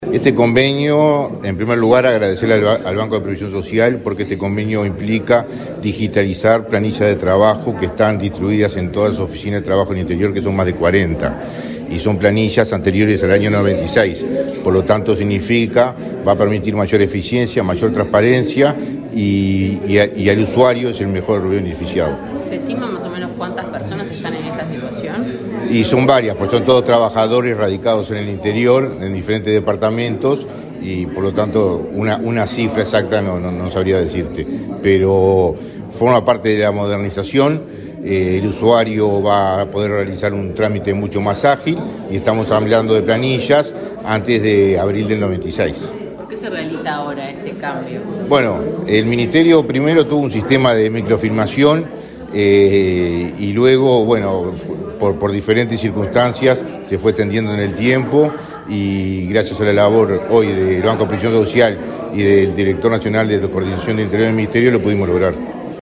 Declaraciones del ministro de Trabajo, Mario Arizti
El ministro de Trabajo, Mario Arizti, dialogó con la prensa, luego de firmar un convenio con autoridades del Banco de Previsión Social, para la